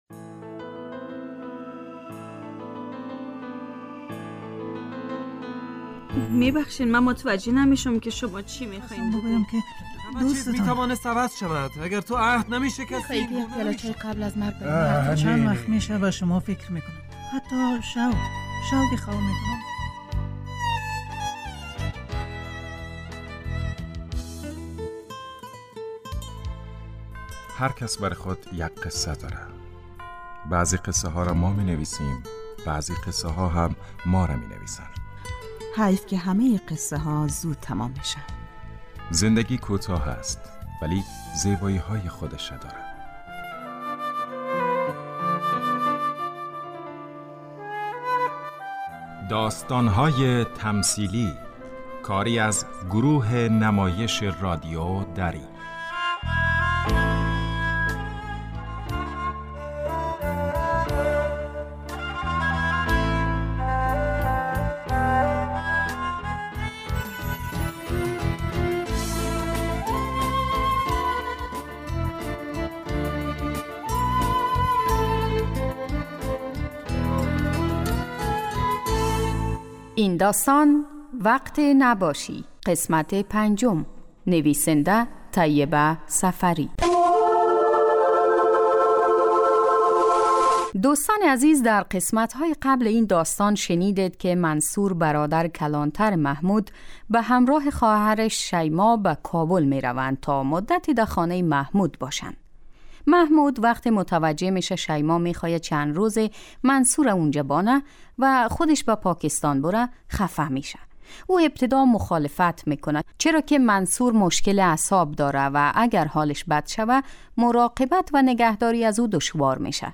داستان تمثیلی